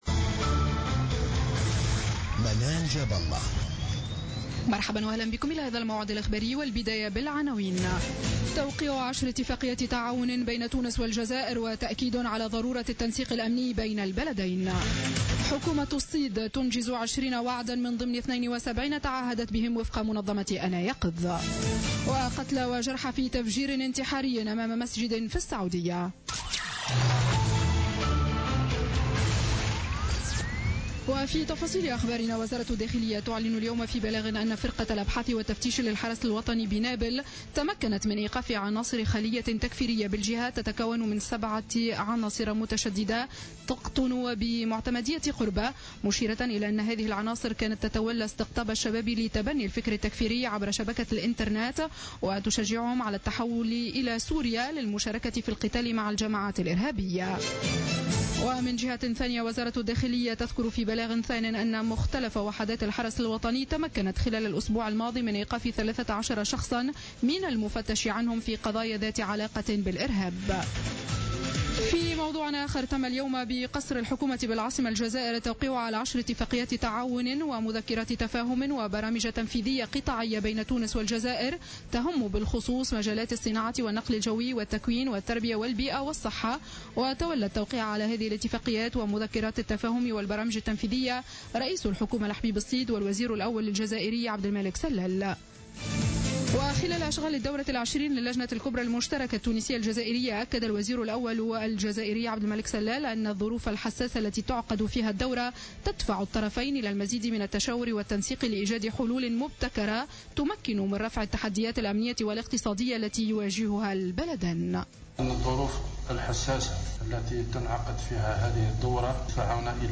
نشرة أخبار السابعة مساء ليوم الإثنين 26 أكتوبر 2015